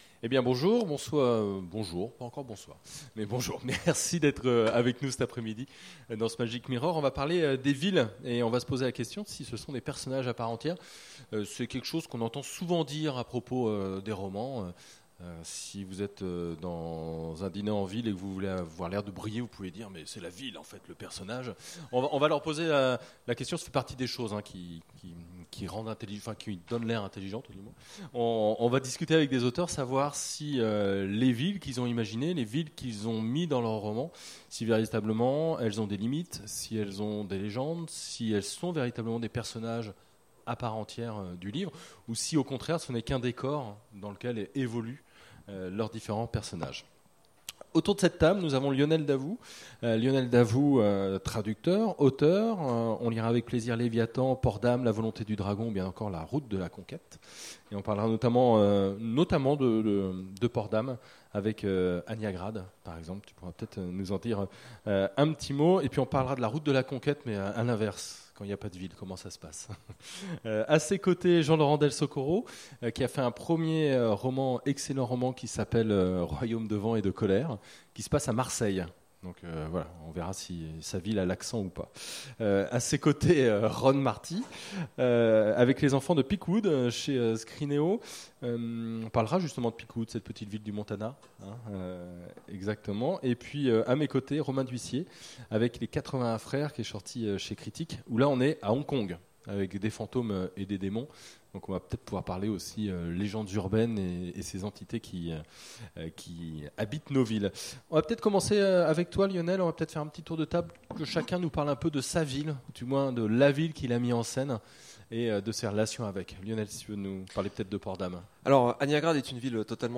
Imaginales 2016 : Conférence Les villes…